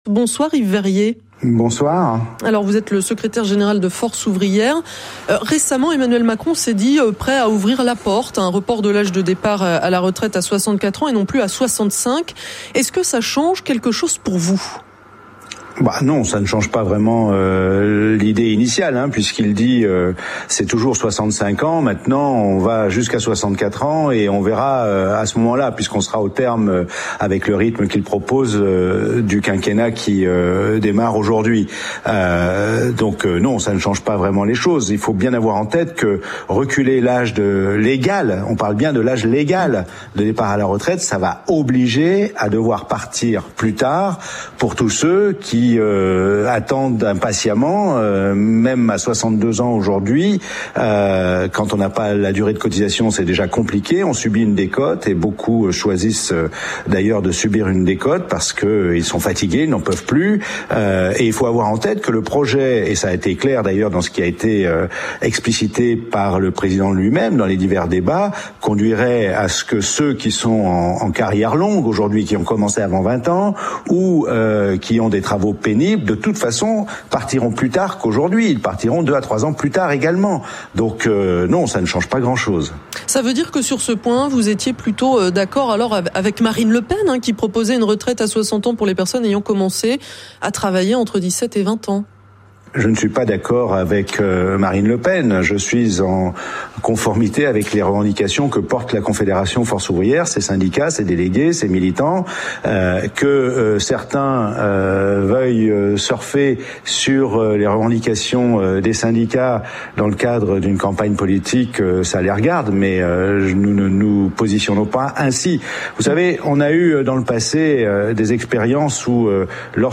Yves Veyrier, Secrétaire général de FO, réagissait à l’élection du Président Macron, dimanche 24 avril sur RCF